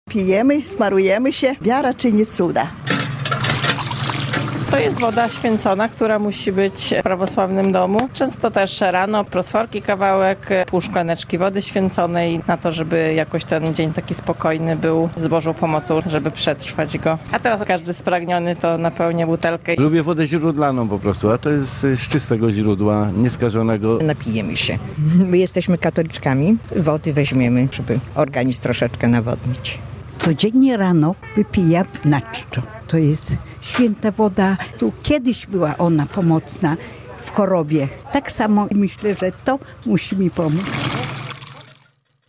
U podnóża Świętej Góry jest kapliczka z cudownym źródłem - relacja